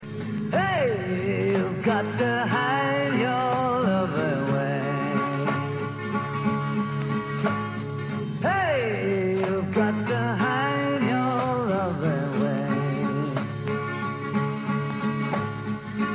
I recorded it off vinyl